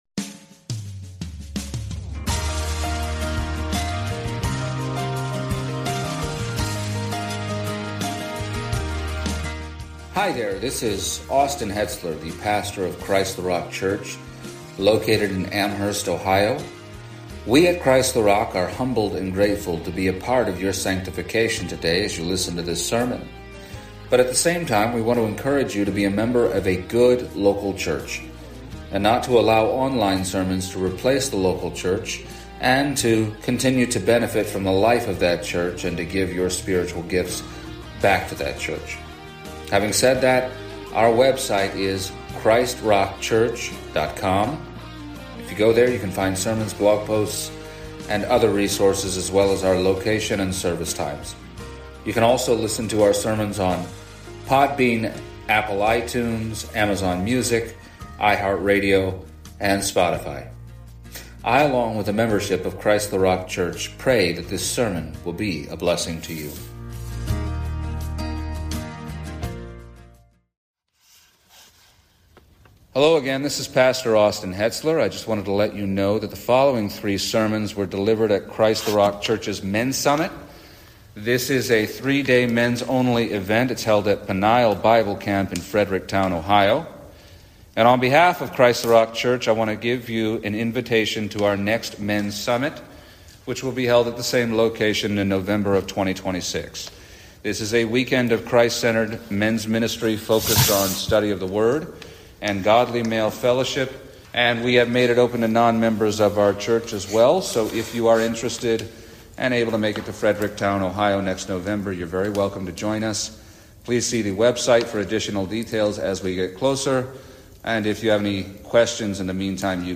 The first of three messages given at the 2025 CtRC Men’s Summit